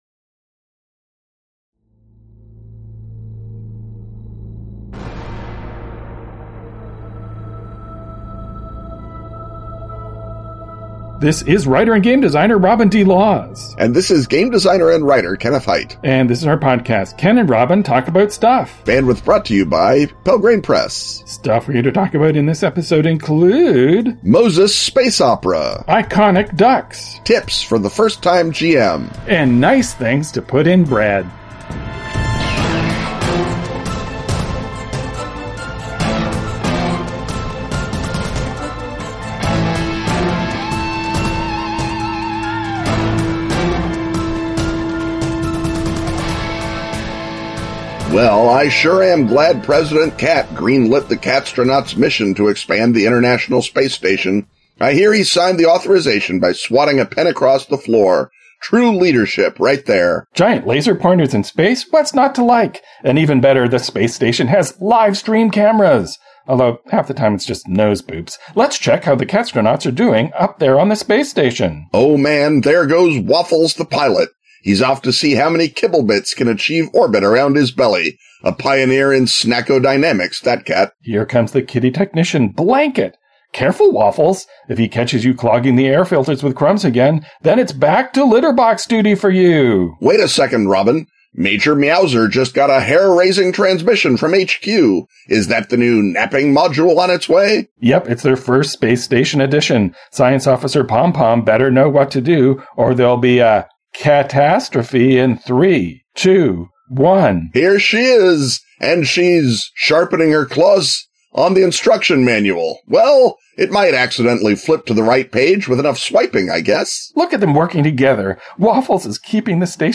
As you may have heard in our previous episode, a mysterious technical event ate our recording of our live episode at this year’s Dragonmeet. In the face of this tragedy we prevail with a not at all convincing recreation of the in-person event, with better sound quality and much worse applause.